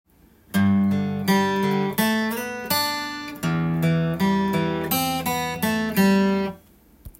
アルペジオ例
例の①～④すべて　ルート音からアルペジオが始まっています。
メジャースケールと言われるドレミファソラシが